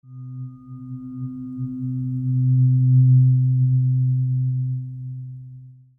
The Otto tuning forks are made from the highest quality aluminium, producing long and enduring tones.
C 128Hz Otto Tuning Fork
The C 128Hz tuning fork is the shortest of the Otto tuning forks, producing the highest frequency in the set.
128Hz-Tuning-Fork.mp3